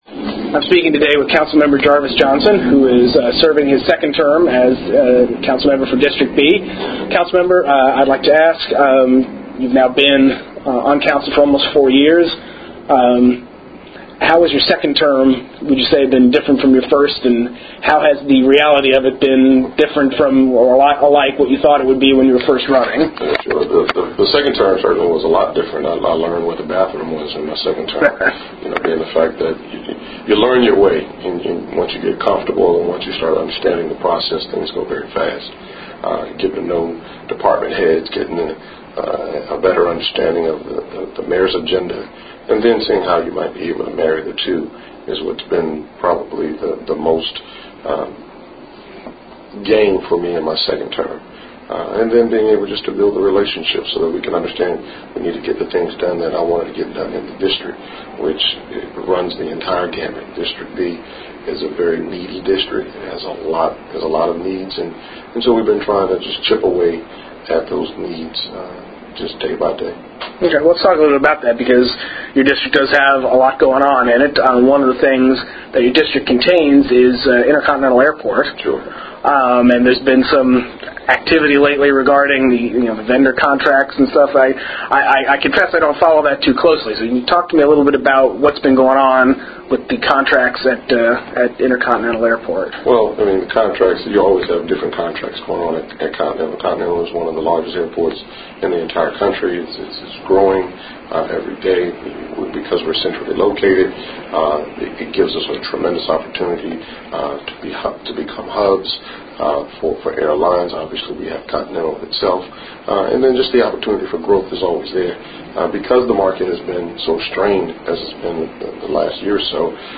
Interview with Council Member Jarvis Johnson